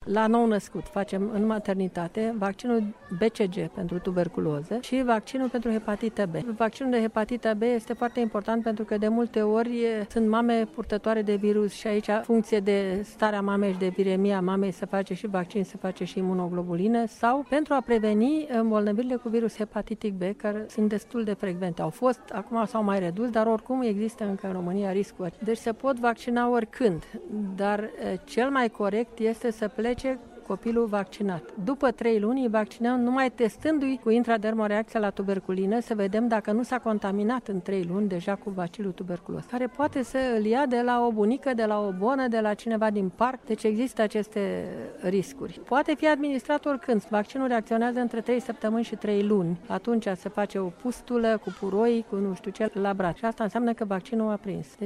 Medic neonatolog